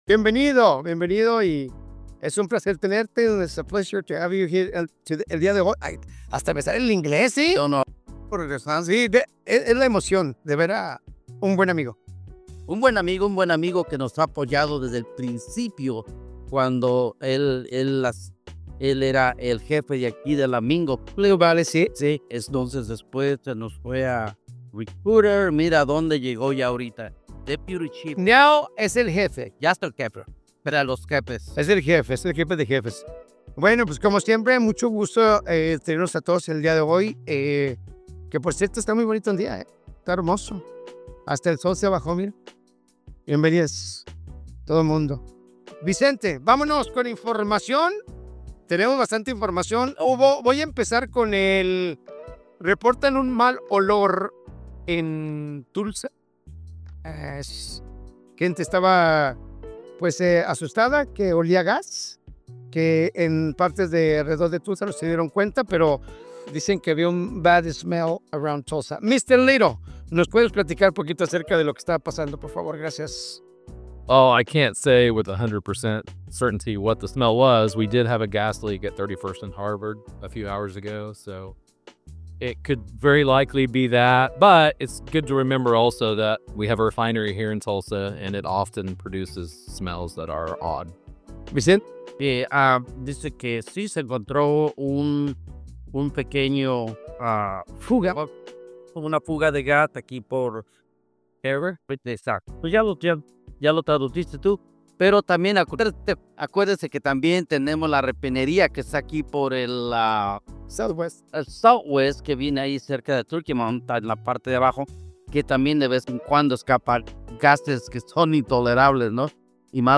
En esta emisión, contamos con la participación Sub-Jefe de Policía de Tulsa Mark Wollmershauser representantes del Departamento de Bomberos, representantes de la oficina del Sheriff y de la fiscalía, para discutir temas prioritarios de seguridad ciudadana y servicios para la comunidad de Tulsa.